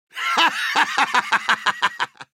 Звук солдатского смеха из Team Fortress 2